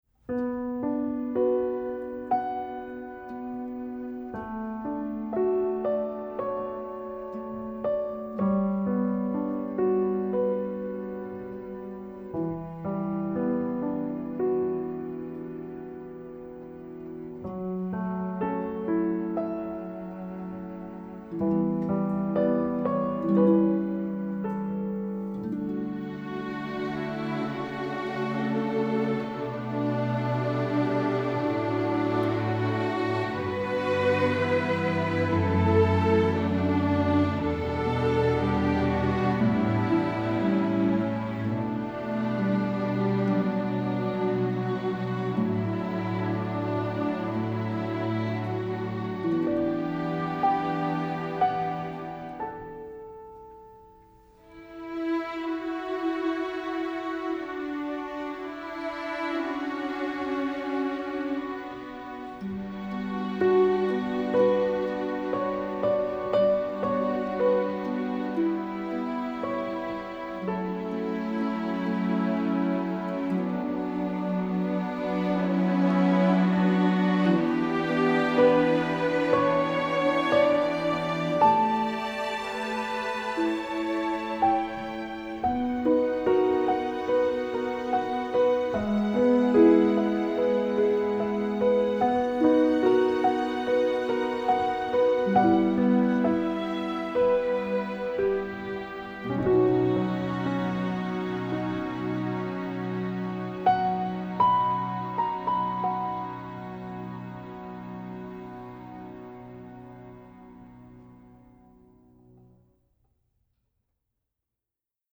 Sound clips from the film score